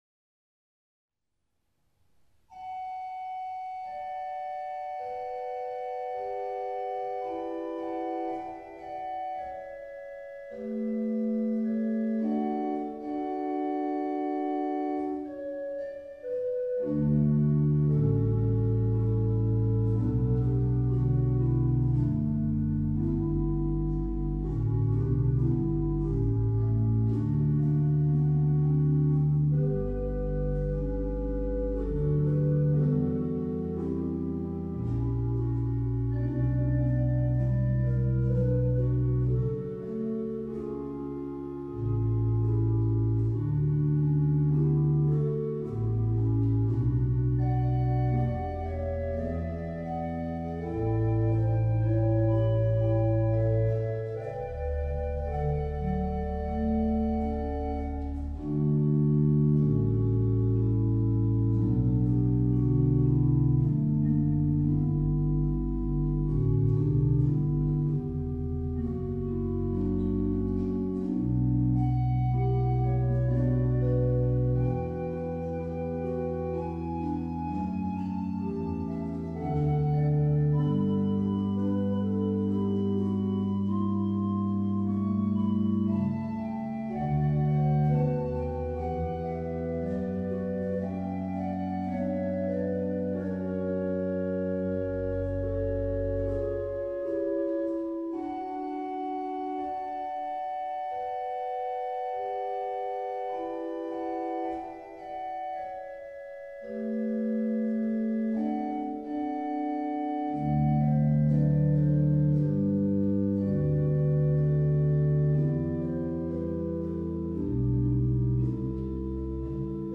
Orgel Heiligenloh
Ausschnitte aus dem Konzert: